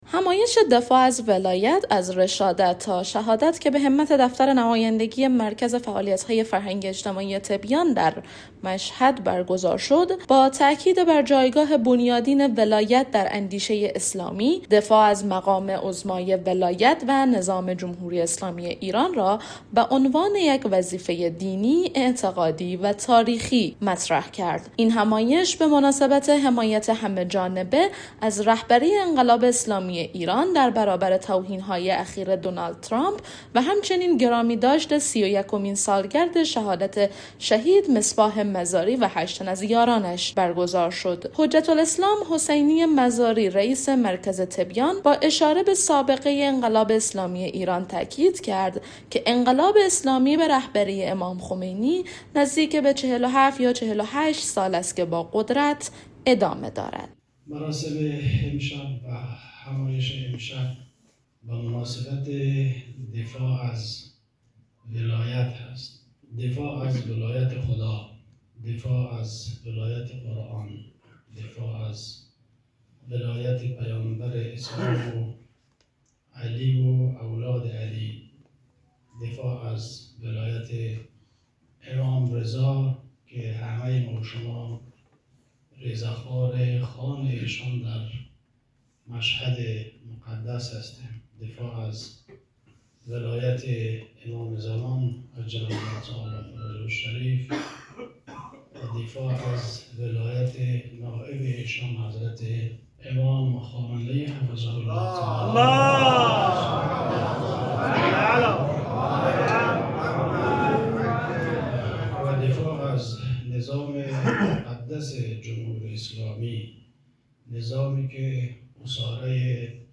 خبرنگار رادیو دری